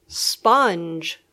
Pronunciación
sponge.mp3